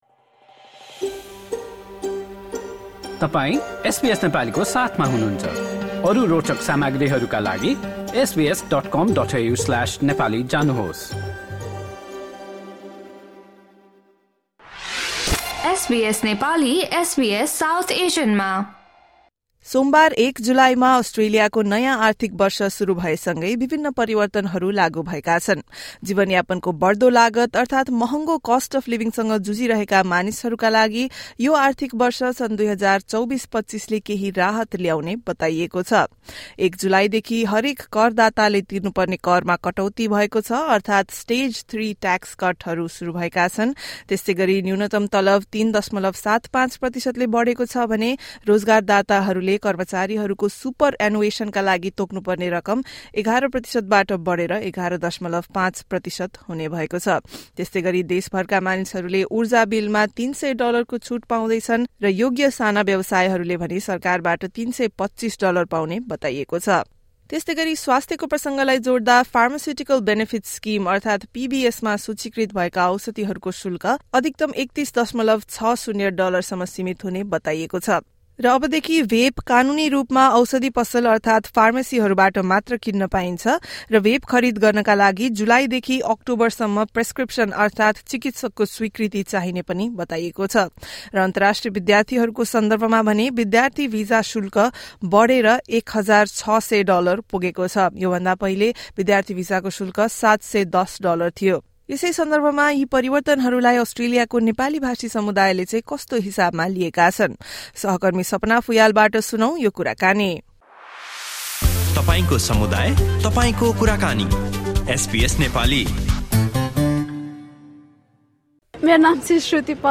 Nepali community members spoke to SBS Nepali about the new financial year changes.